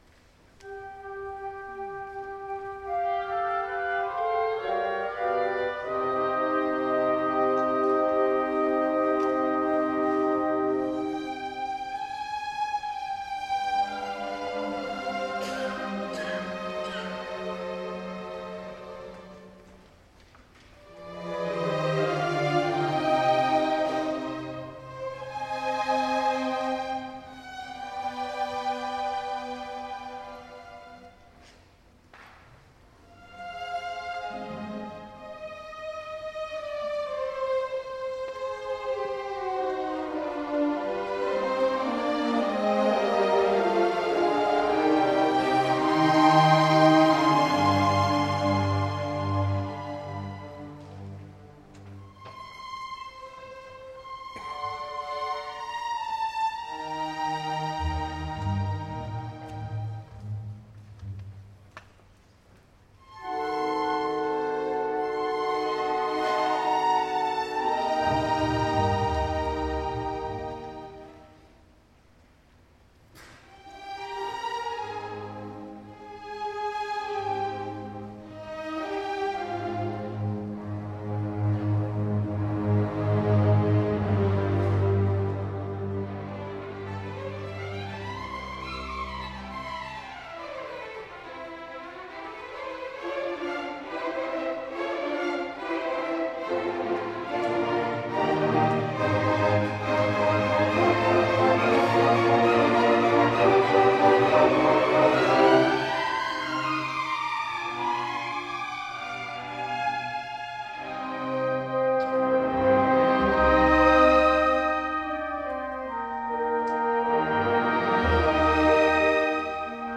Orchestra
Style: Classical